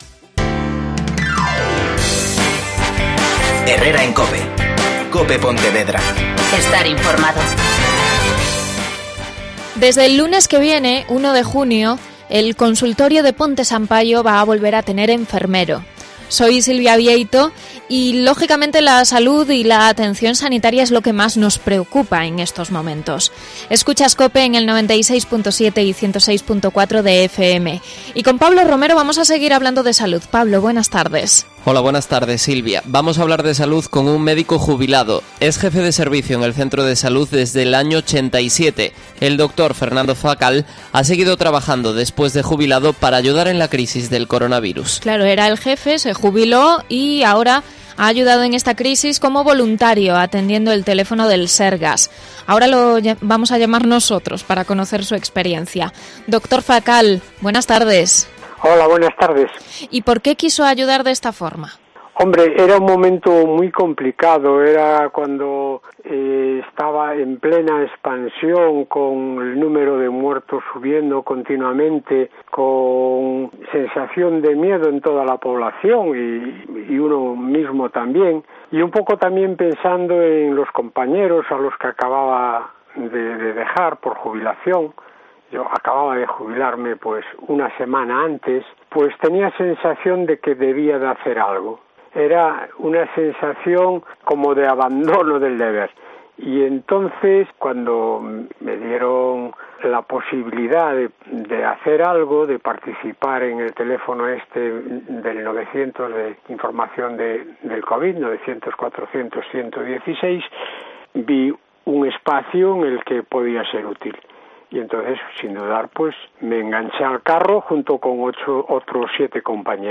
Entrevista a uno de los médicos voluntarios en el teléfono del coronavirus de Galicia